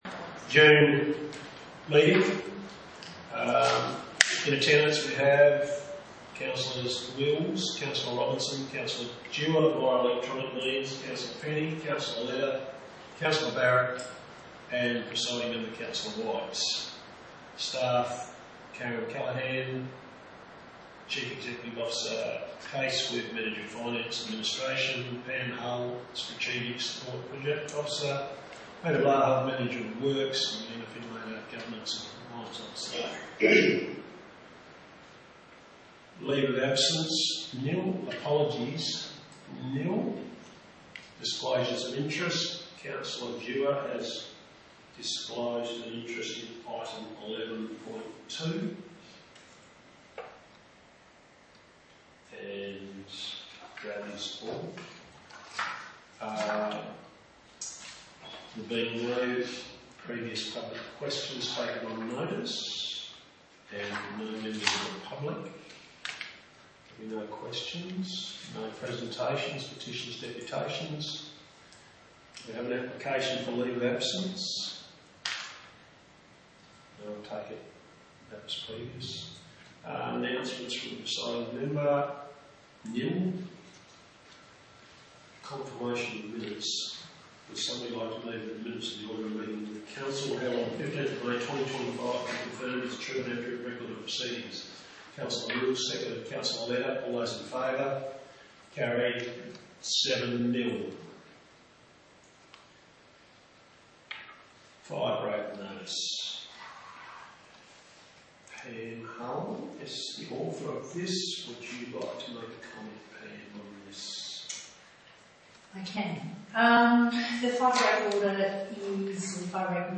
19 June 2025 Ordinary Meeting of Council » Shire of Broomehill-Tambellup